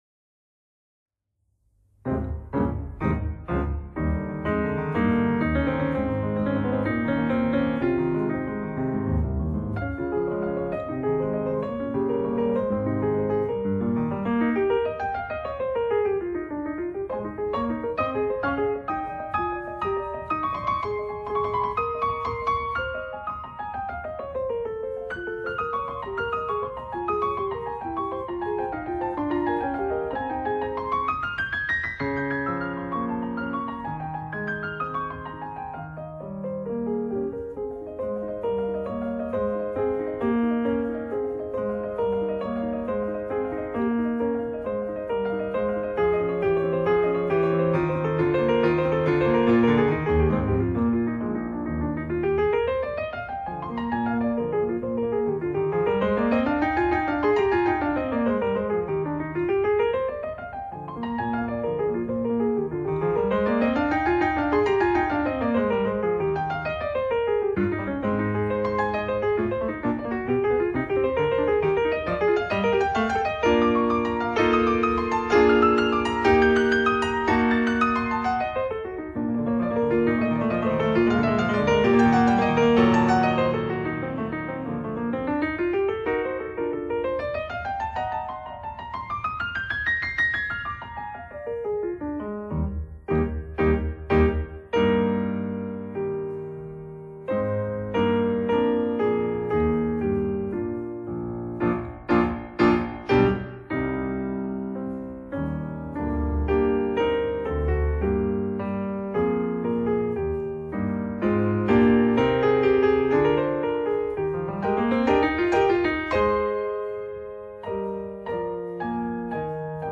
鋼琴奏鳴曲輯